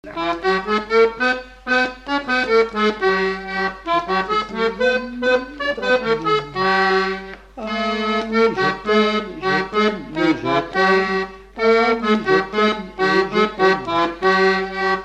Lucs-sur-Boulogne (Les)
Chants brefs - A danser
Résumé instrumental
danse : scottish (autres)